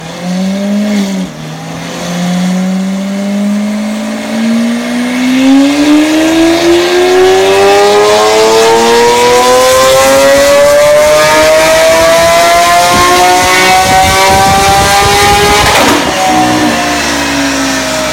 Lamborghini Aventador dyno pull! Sound sound effects free download